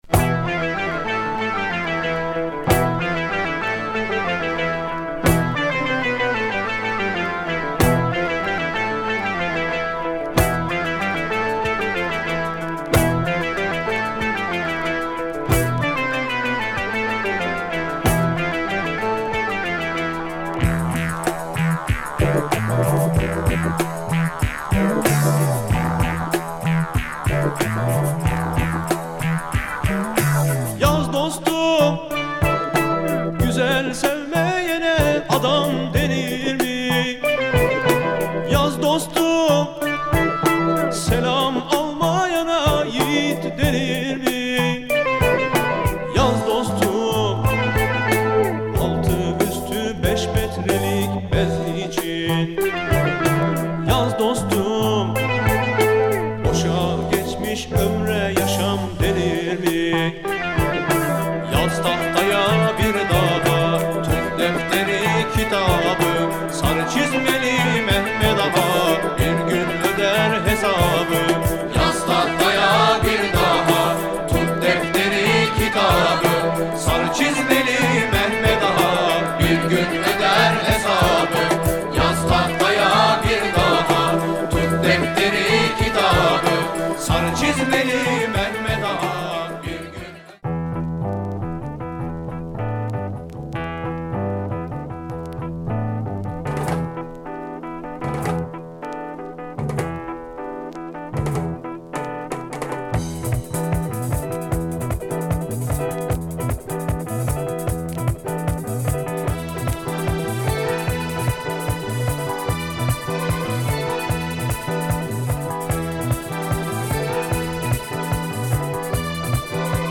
Killer and cult Turkish psych funk !
Anadolu pop at its best !